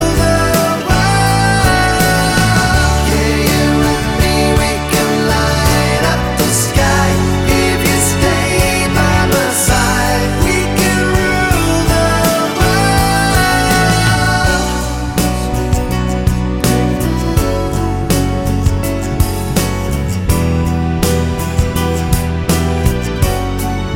No Electric Guitars Pop (1990s) 4:01 Buy £1.50